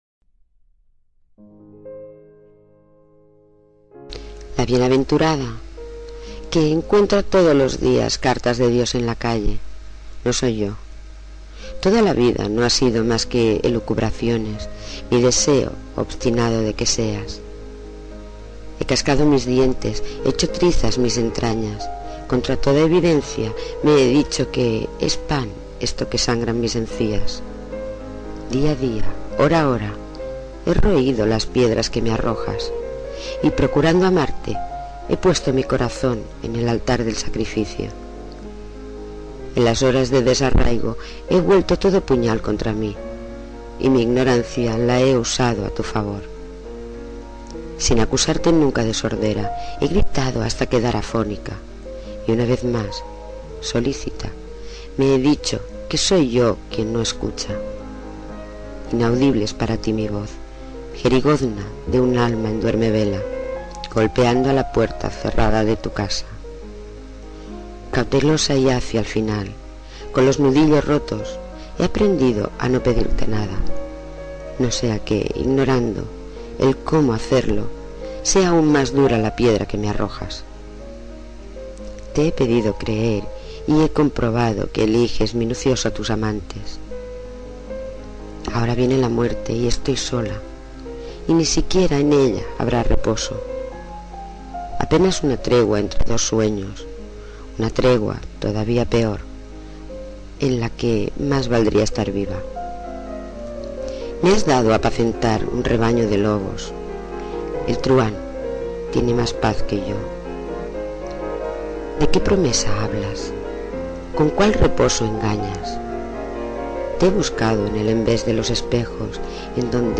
Inicio Multimedia Audiopoemas Extranjera en la niebla.